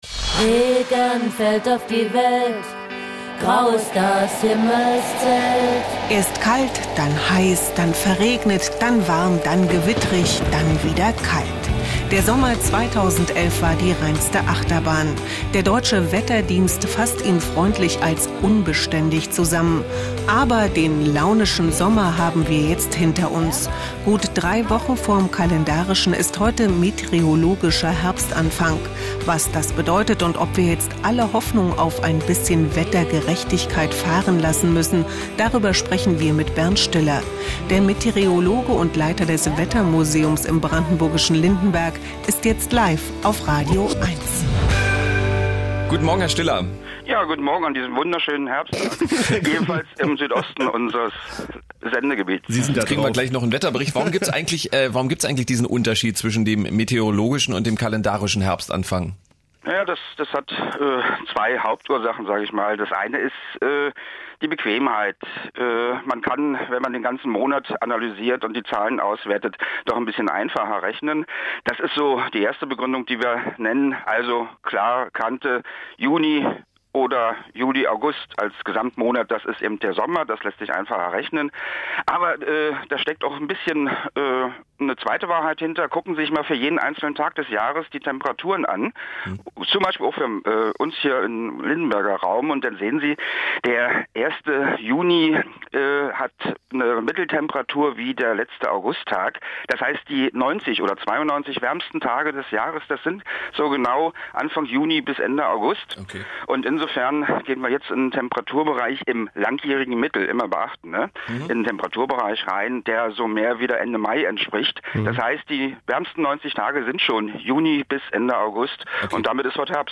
06:45 Uhr 4-Minuten-Telefoninterview zum Herbstanfang
Aufnahmedatum: live